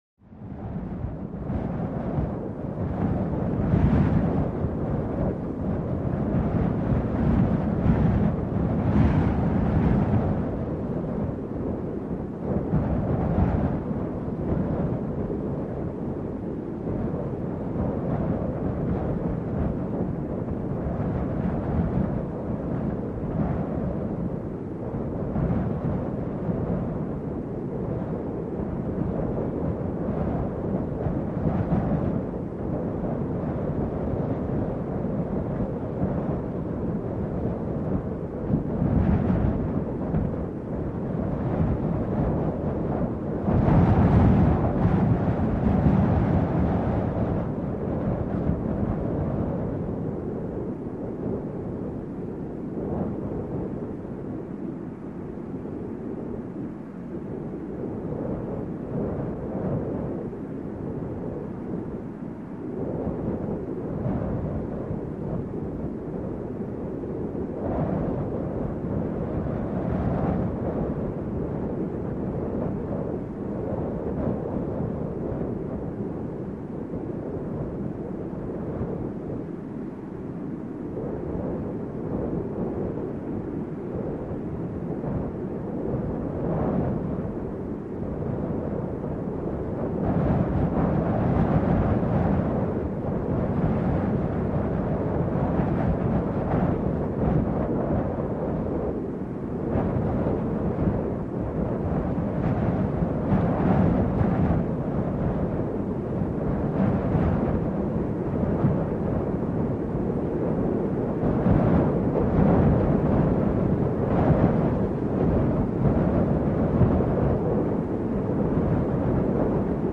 Gusts; Medium To Heavy, With Swirling Low-end At Times Howling.